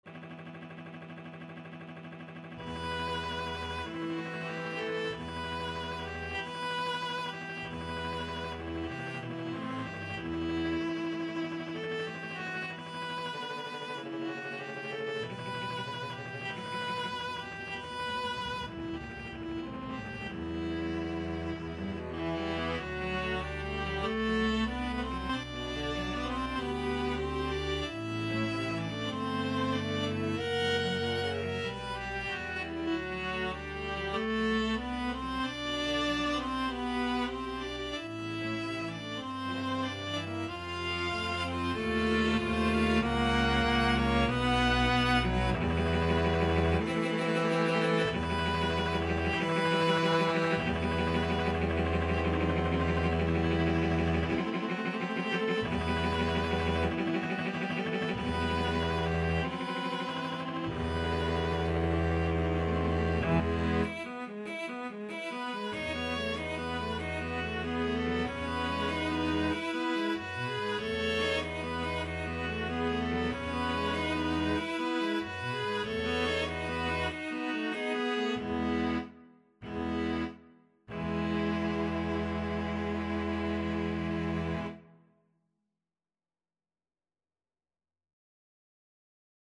4/4 (View more 4/4 Music)
Andante = 95
Classical (View more Classical String Quartet Music)